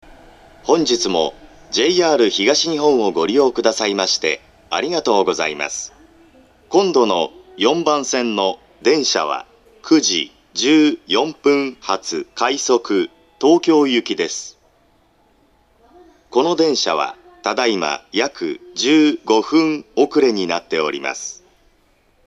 ２０１８年１０月１５日からは快速線ホームのみ発車メロディーが必ず最後まで流れるようになりました。
なお、発車ベルスイッチを切ったところから戸閉め放送が被ります。
発車メロディー（ｔｗｉｌｉｇｈｔ）
ただ到着放送の鳴動が遅いため、発車メロディーに被りやすいのが難点です。
kichijoji4bansen-jihatu3.mp3